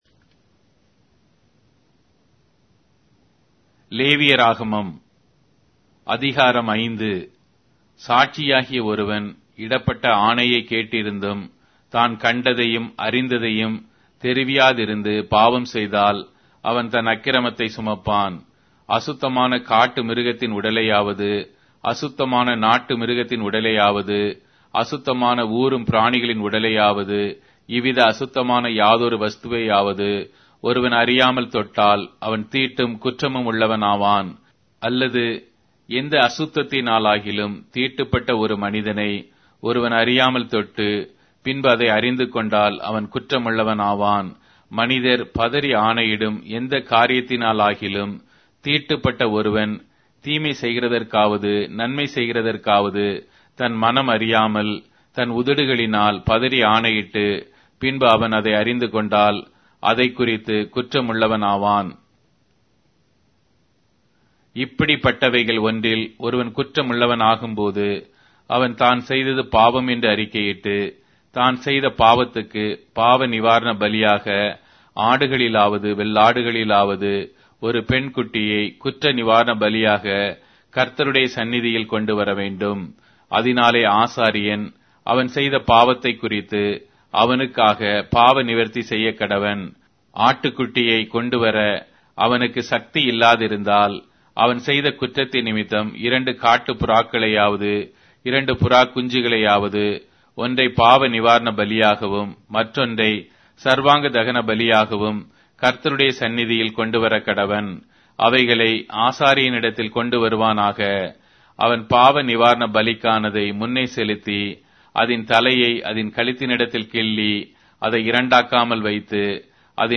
Tamil Audio Bible - Leviticus 13 in Pav bible version